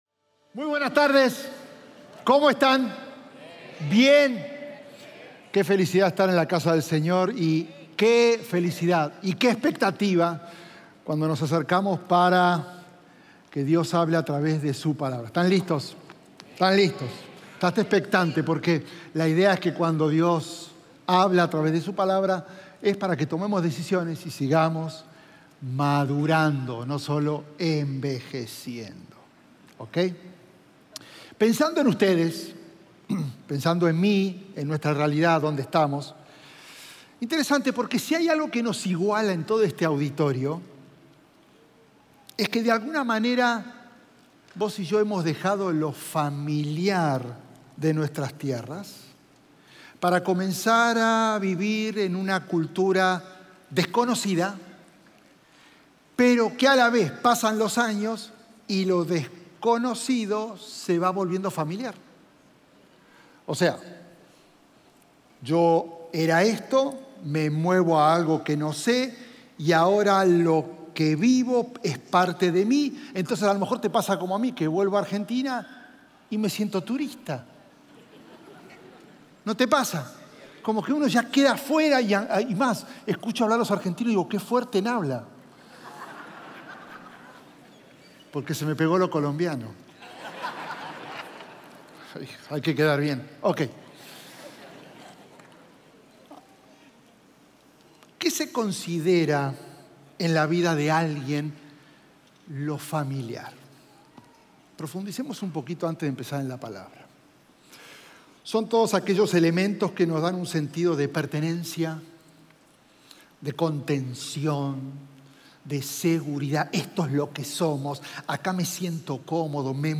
Un mensaje de la serie "Más."